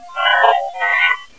What follows is a huge collection of ghost voice recordings we have aquired there.
Some of the EVP's had to be amplified quite a bit....others were so strong and
This next inquisitive spirit is perhaps talking about us
as we arrived and began taping.